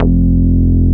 P MOOG F2MF.wav